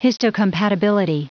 Prononciation du mot histocompatibility en anglais (fichier audio)
Prononciation du mot : histocompatibility